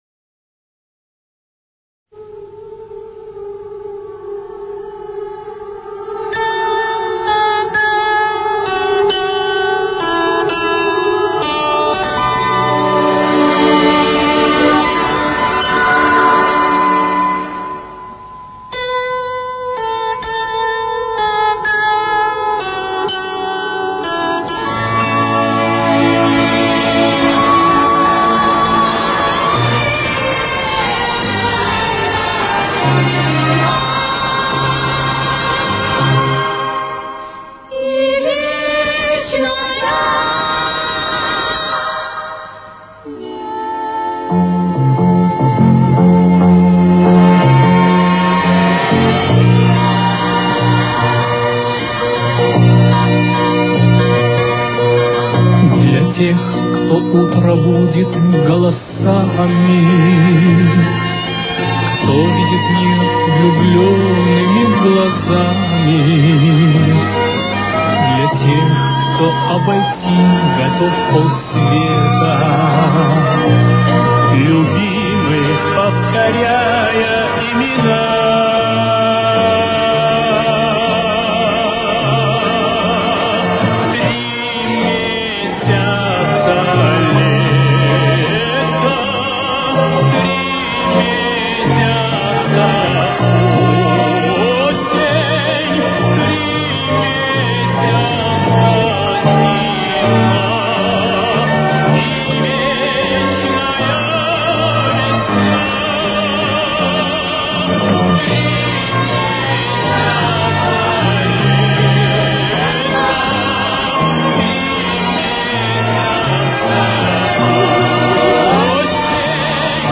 Тональность: Си-бемоль мажор. Темп: 77.